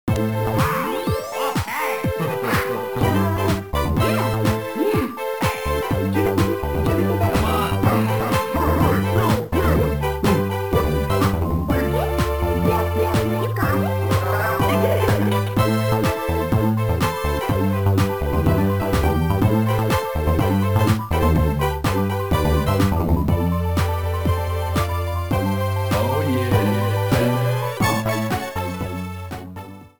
title screen music